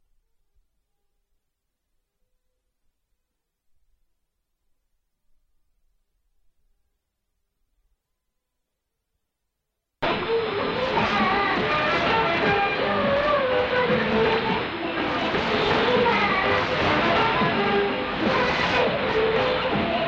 Reception comparisons
Second 10 - 20: Icom IC-R75 Kiwa Mod.